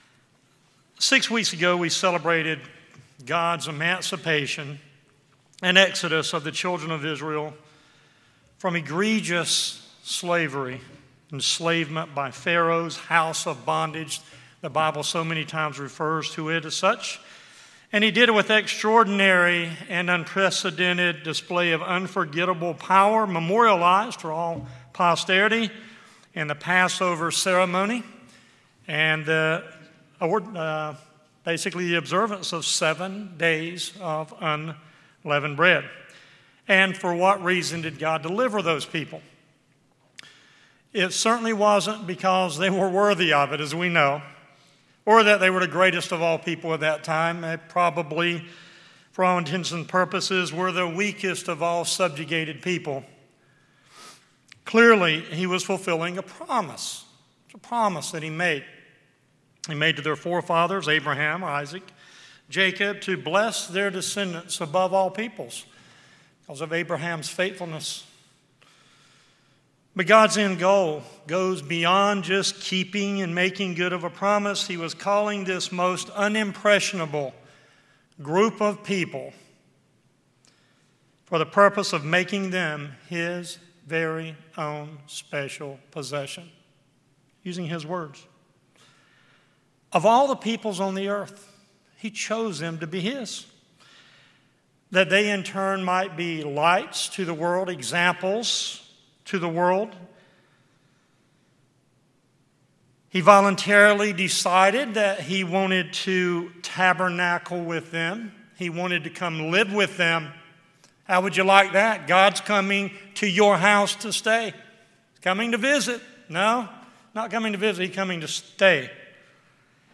A message about how we should follow up on the blessings of the spring holy days.